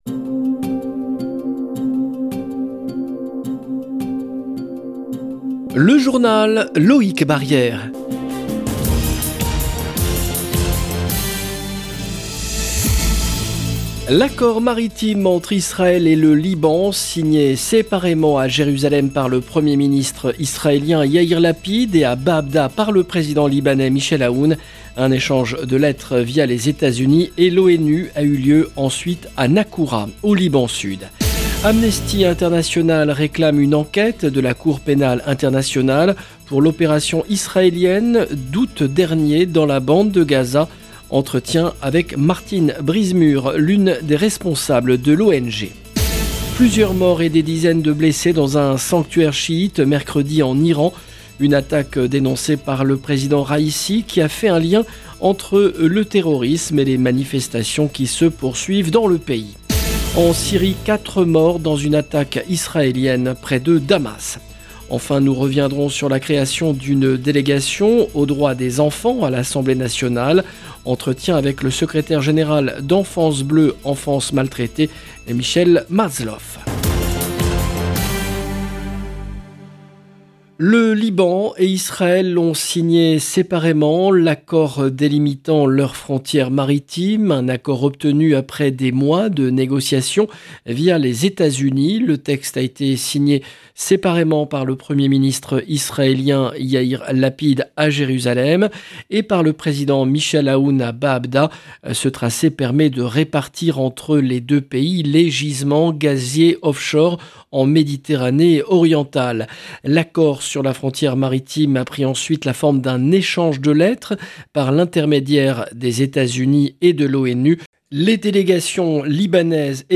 JOURNAL EN LANGUE FRANÇAISE